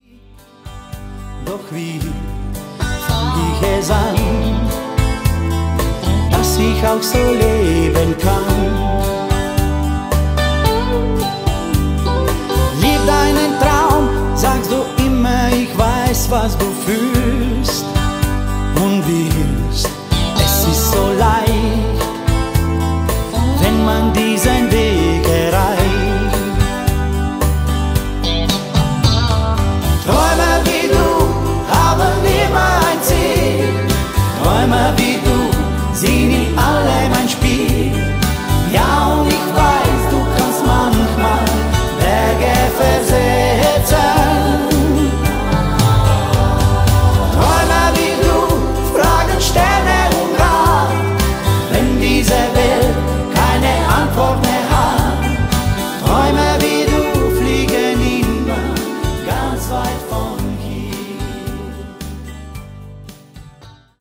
Volkstümlich / Oberkrainer 100% LIVE
• Coverband
• Tanzbands